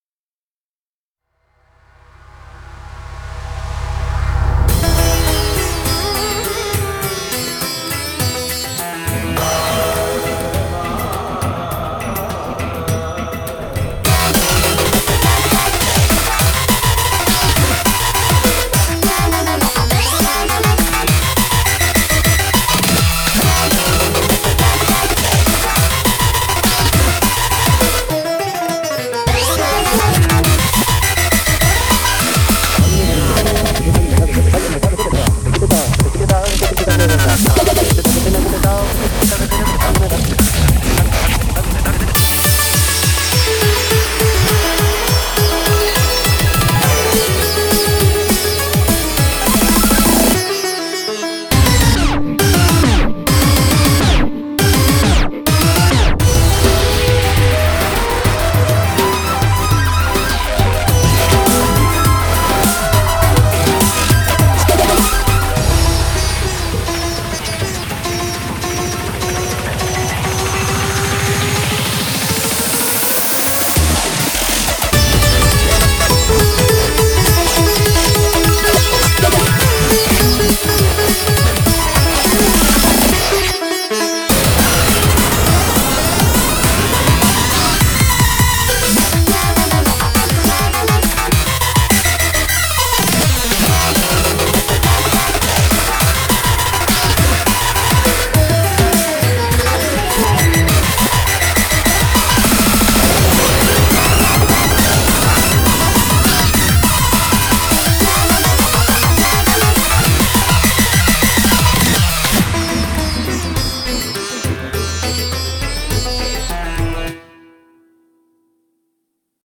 BPM103-205
Audio QualityPerfect (High Quality)